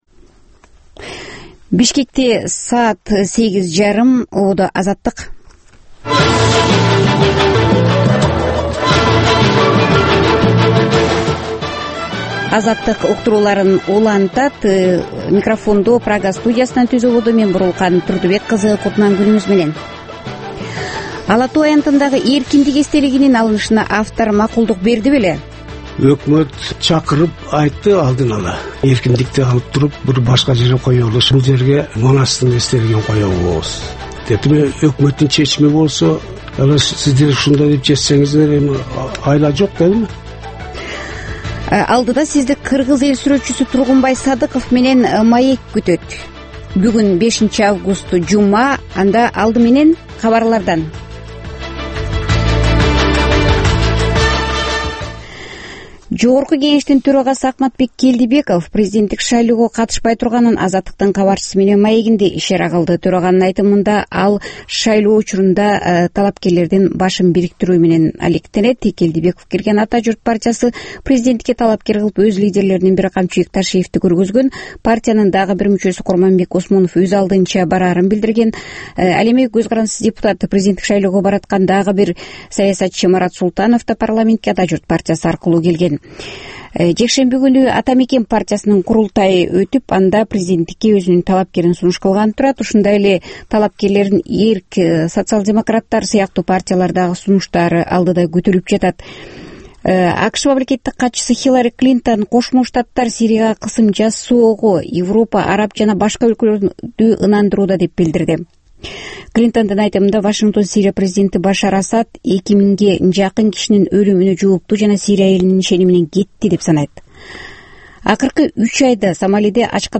Бул таңкы үналгы берүүнүн 30 мүнөттүк кайталоосу жергиликтүү жана эл аралык кабарлар, ар кыл орчун окуялар тууралуу репортаж, маек, күндөлүк басма сөзгө баяндама, «Коом жана турмуш» түрмөгүнүн алкагындагы тегерек үстөл баарлашуусу, талкуу, аналитикалык баян, сереп жана башка берүүлөрдөн турат. "Азаттык үналгысынын" бул берүүсү Бишкек убакыты боюнча саат 08:30дан 09:00га чейин обого чыгарылат.